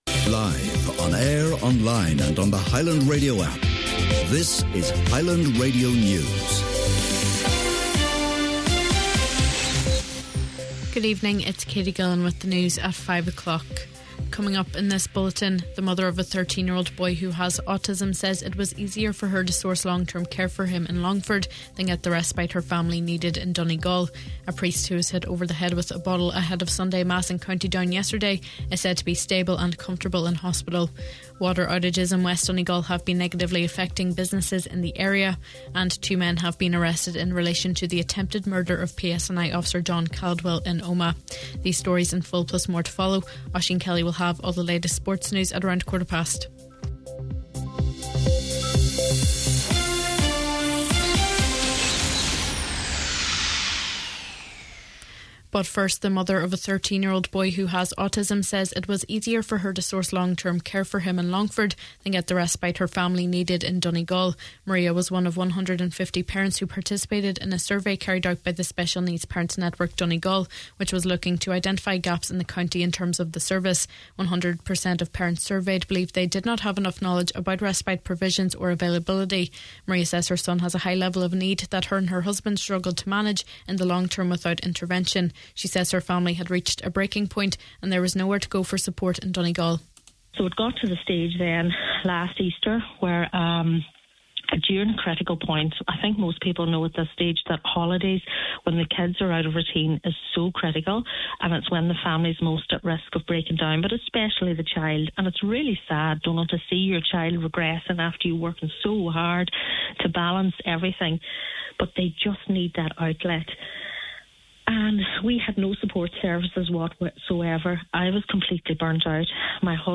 Main Evening News, Sport and Obituary Notices – Monday August 11th